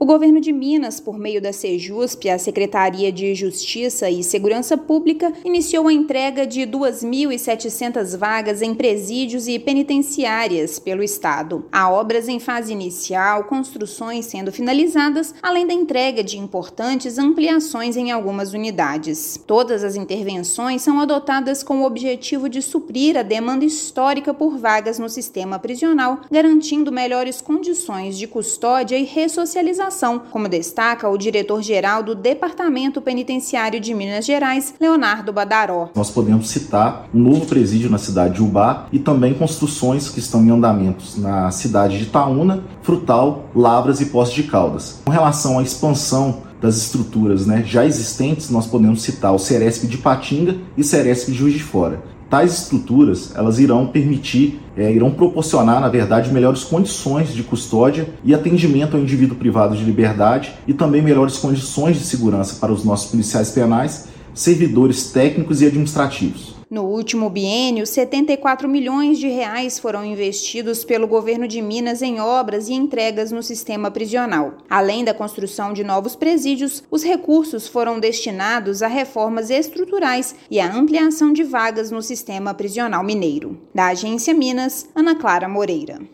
[RÁDIO] Segurança Pública cria 2,7 mil novas vagas no sistema prisional de Minas Gerais
Investimento histórico garante melhores condições de custódia e ressocialização para detentos de Norte a Sul do estado. Ouça matéria de rádio.